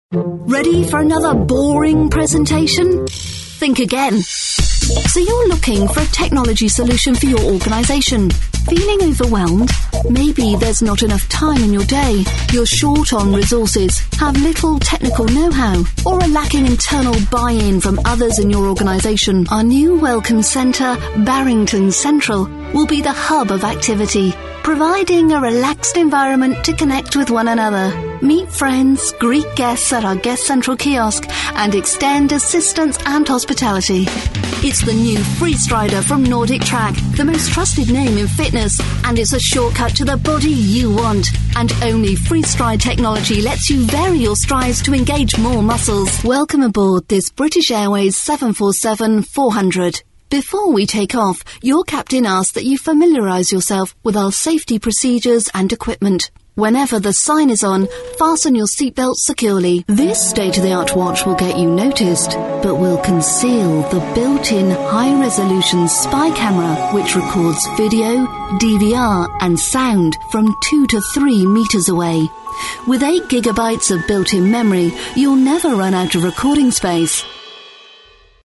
Native speaker Female 30-50 lat
Phone Announcementfrom 50 EUR
Pure, classic, engaging English sound.